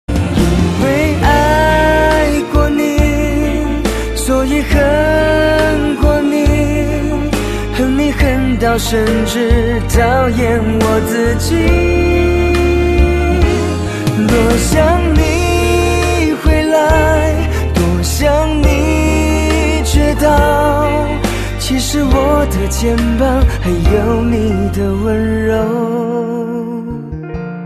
M4R铃声, MP3铃声, 华语歌曲 110 首发日期：2018-05-15 16:05 星期二